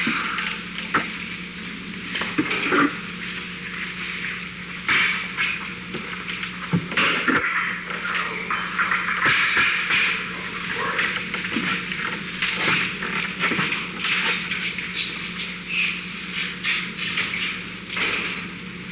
Secret White House Tapes
Location: Oval Office
The President met with an unknown person.